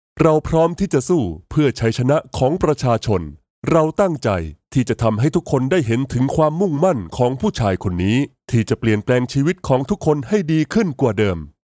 Thaïlandais voice actor
Annonces politiques